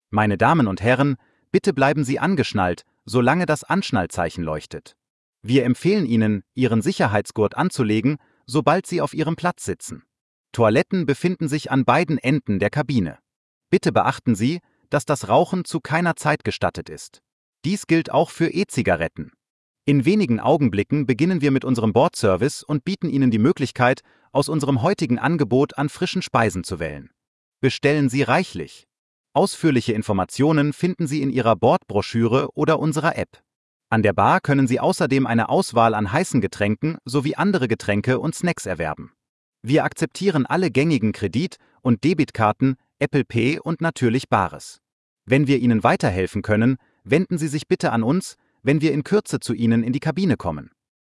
AfterTakeoff.ogg